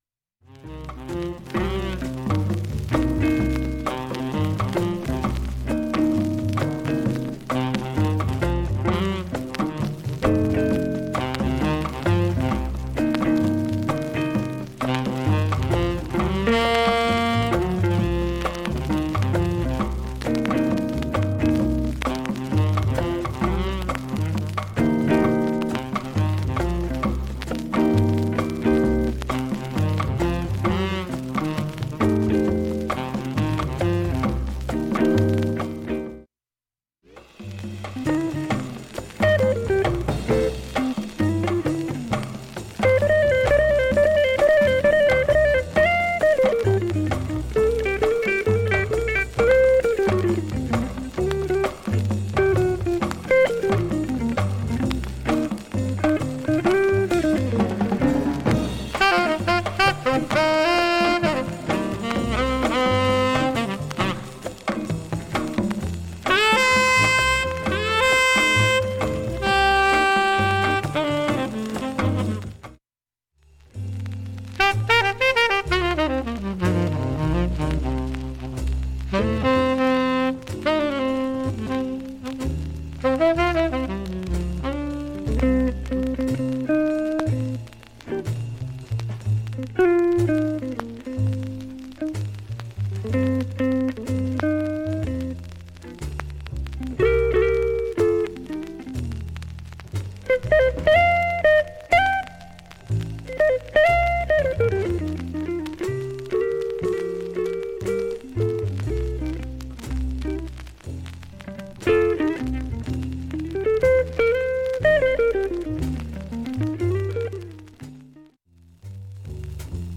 溝が深いせいかノイズ少な目）
全体に軽いバックチリが出ていますが
バックチリもかなり少なくなります。
特に全体にもひどいジりパチ、プツ音もありません。
◆ＵＳＡ盤オリジナルMono